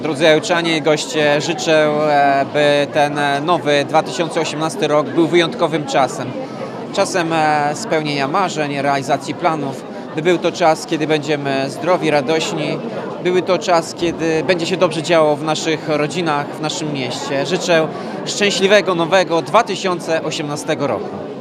Gala Sylwestrowa
Noworoczne życzenia złożył wszystkim mieszkańcom Ełku prezydent – Tomasz Andrukiewicz